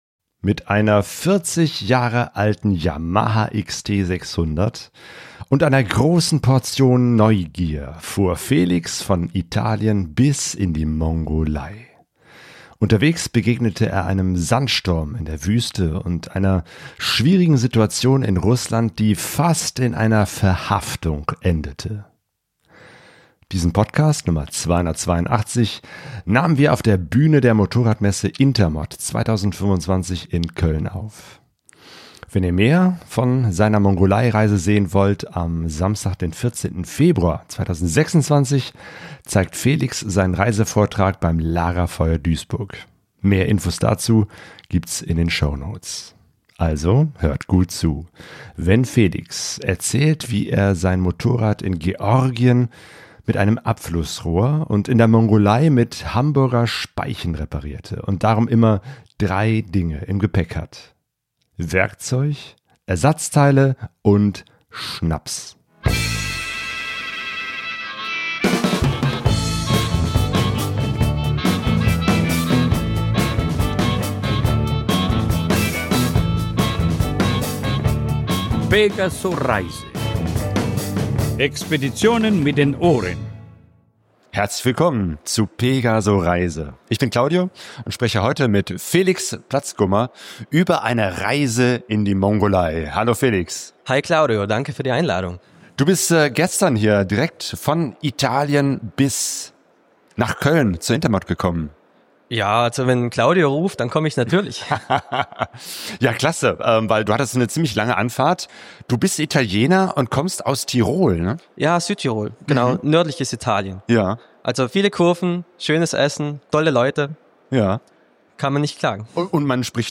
Interview, das wir auf der Bühne der Motorradmesse Intermot 2025 aufnahmen